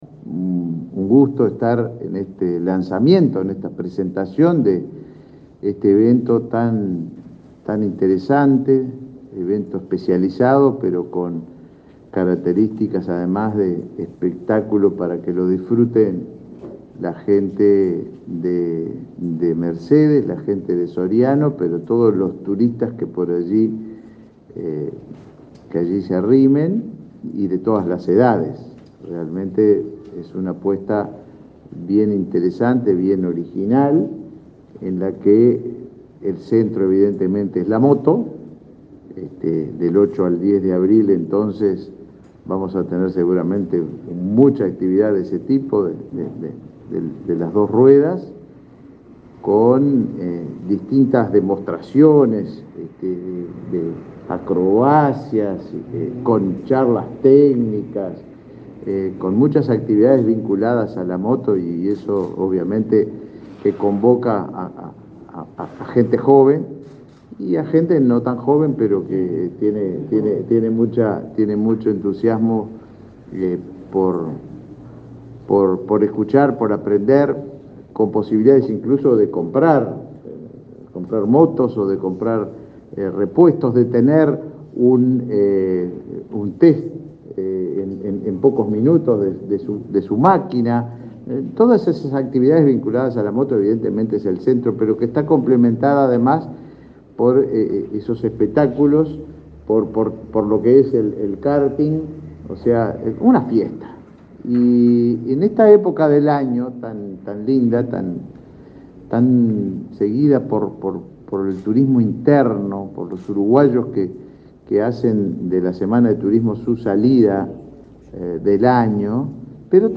Palabras del ministro de Turismo, Tabaré Viera
El ministro de Turismo, Tabaré Viera, participó este lunes 4, en Montevideo, del lanzamiento de la Expo Moto, que tendrá lugar entre el 8 y 10 de